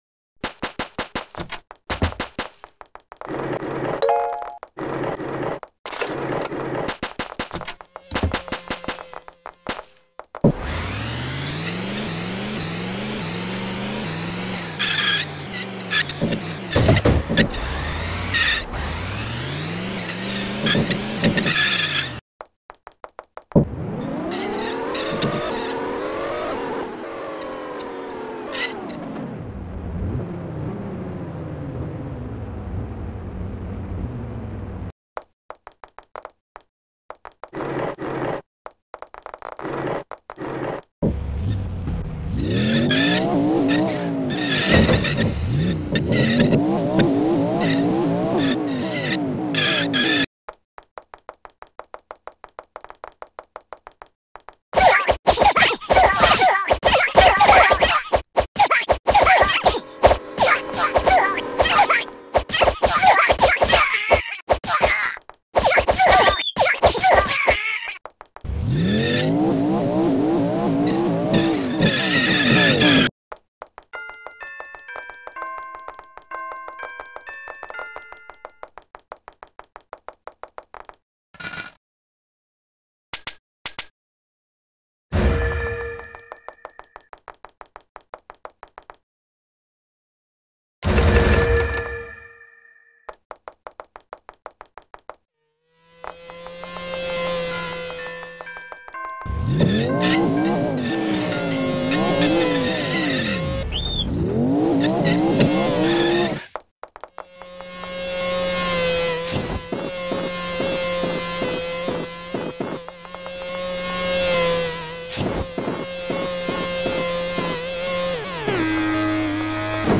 All Ambulance Cars Collection At Sound Effects Free Download